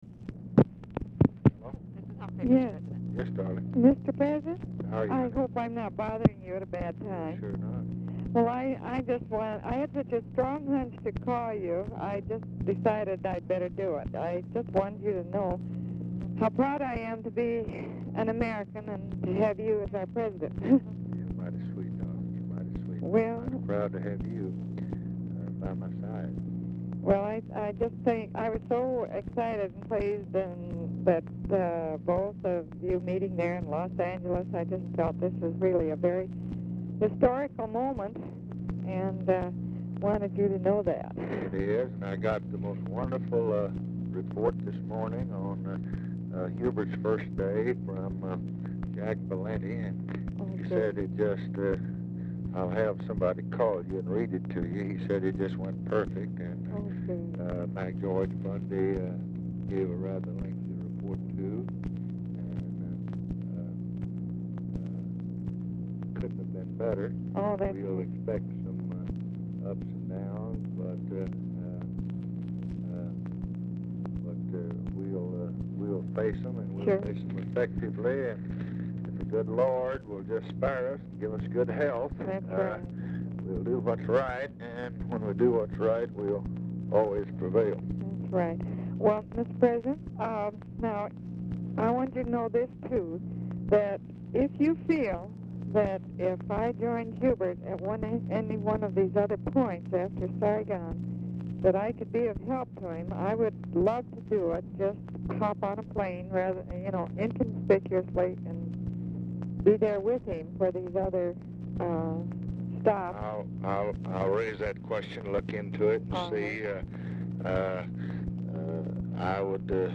Telephone conversation # 9634, sound recording, LBJ and MURIEL HUMPHREY, 2/11/1966, 10:55AM | Discover LBJ
Format Dictation belt
Location Of Speaker 1 Mansion, White House, Washington, DC